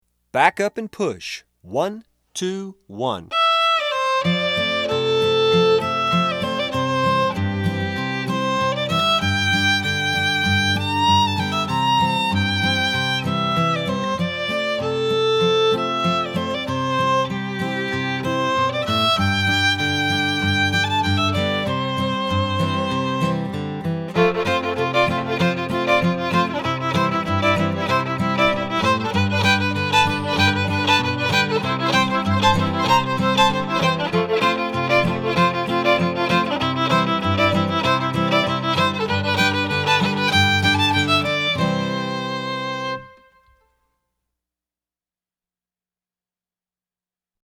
DIGITAL SHEET MUSIC - FIDDLE SOLO
Fiddle Solo, Traditional, Hoedown